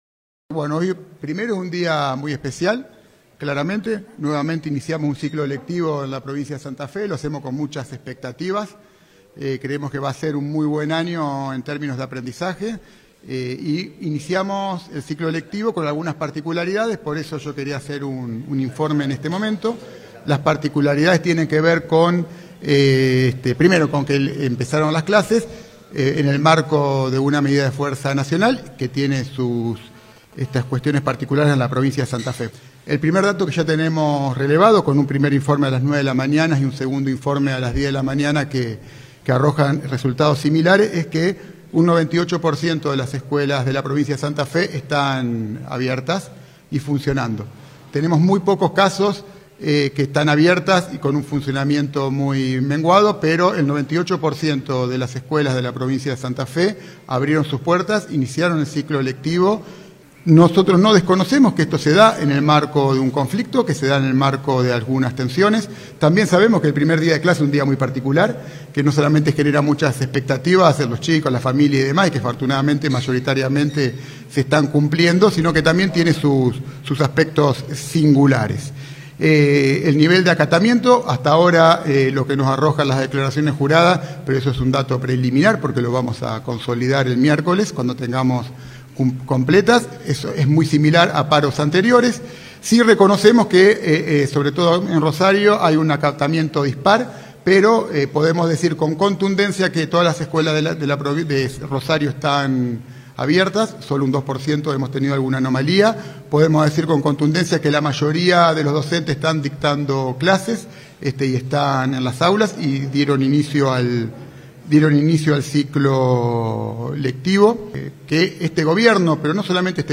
Durante una conferencia de prensa realizada en la sede de Gobierno de Rosario, el funcionario remarcó que “un 98 % de las escuelas están abiertas y funcionando”.
Conferencia de prensa del ministro de Educación.